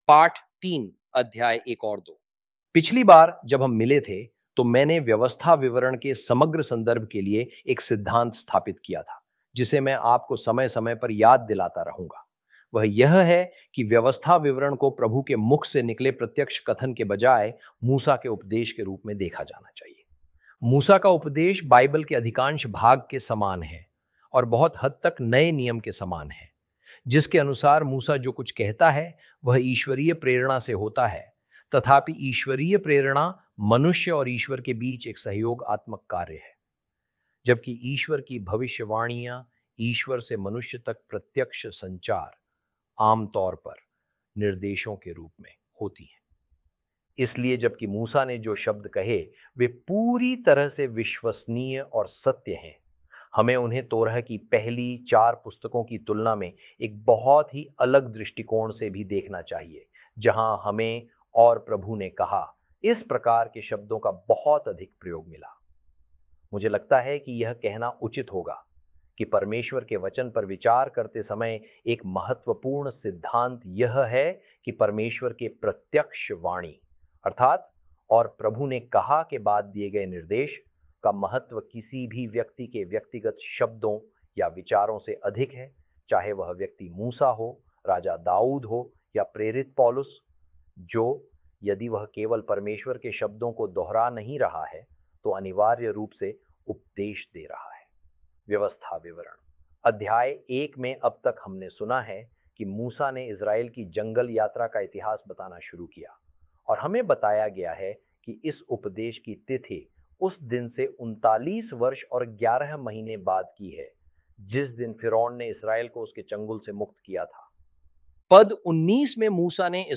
hi-audio-deuteronomy-lesson-3-ch1-ch2.mp3